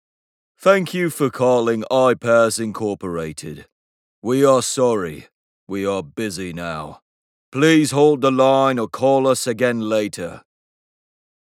キャラクターボイスに特に情熱を持ち、活気に満ちた声と幅広い音域を活かして、 ビデオゲーム、アニメ、オーディオドラマなどで様々なキャラクターに命を吹き込んでいる。
ナレーターランクⅢ（男性）